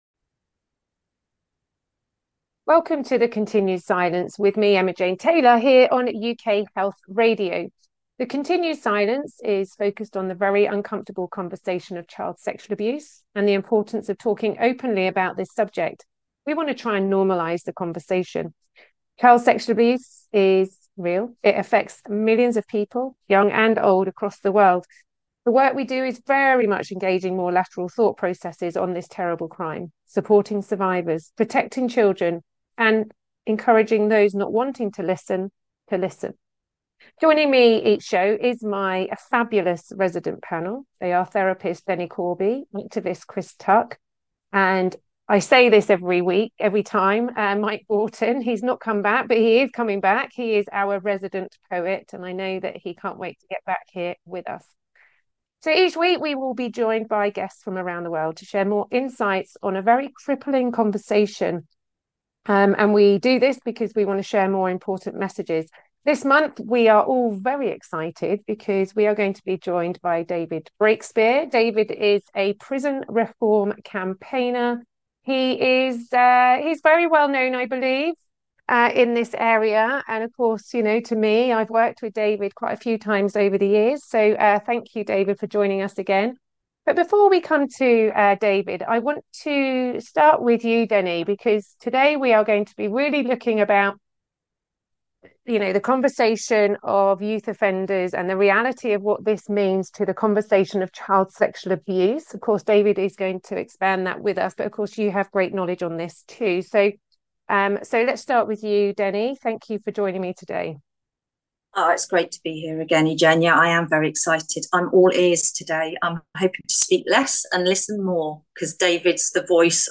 UK Health Radio Podcast